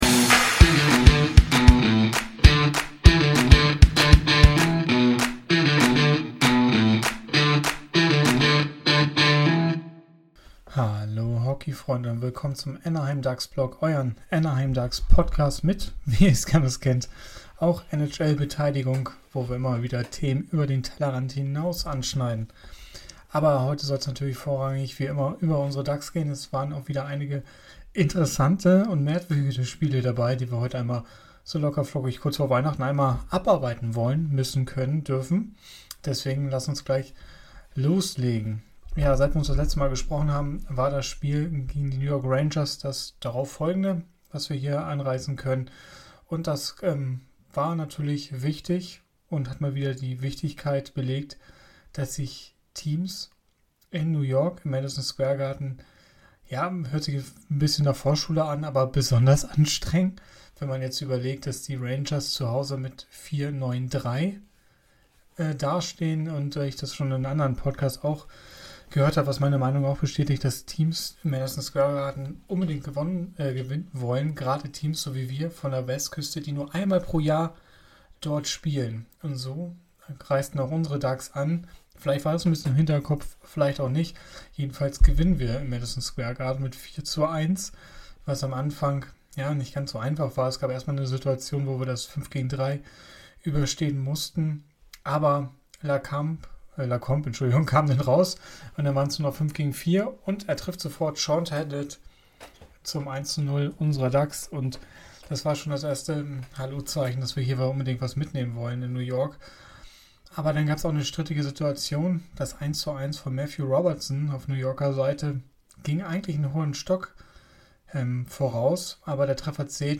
Hallo Hockeyfreunde, mit angeschlagener Stimme besprechen wir heute die jüngsten Spiele der Ducks und analysieren die aktuelle Situation. Dazu schauen wir genau auf die Tabelle und besprechen die Wichtigkeit der nächsten Spiele.